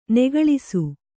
♪ negaḷisu